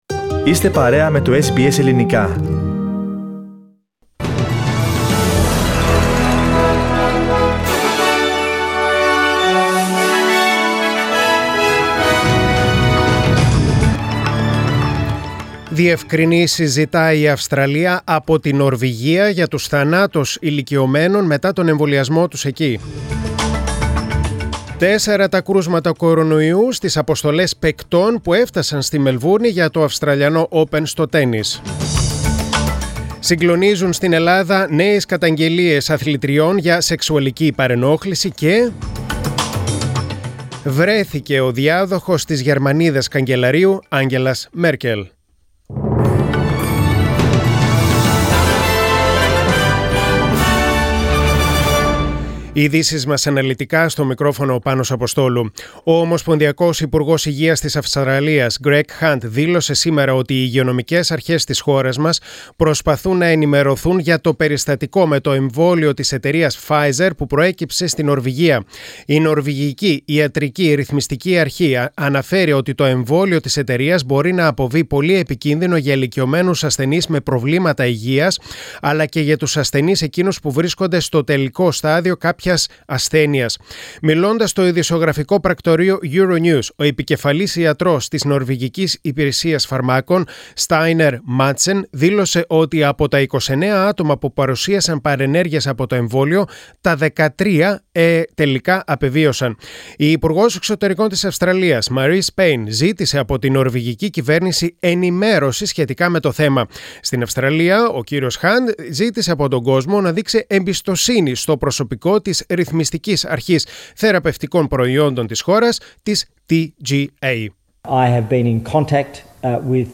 Press Play on the main photo and listen to the News Bulletin (in Greek) Share